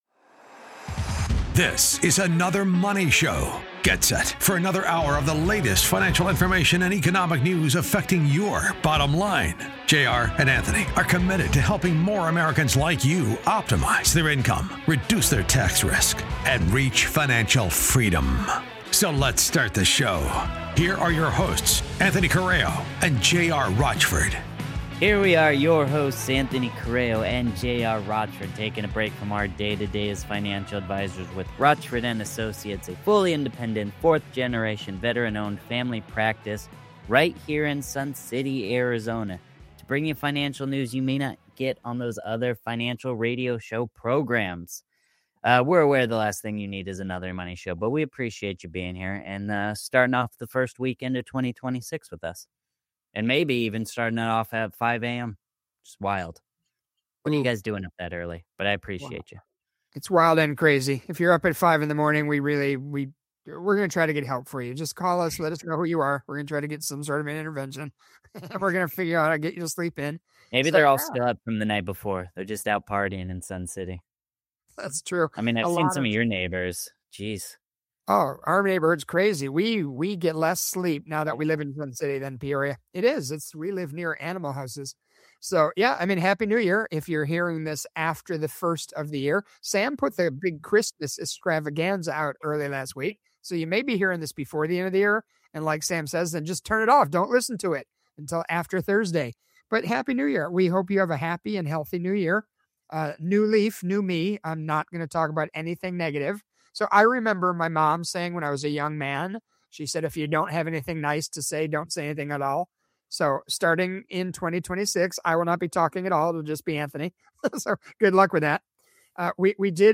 The guys kick off the new year with a wide-ranging conversation about the financial realities many Americans are facing right now. From student loan debt and wage garnishment to the broader cost of education and the growing national debt, they break down the issues that continue to impact households as the calendar turns and people start thinking about what comes next.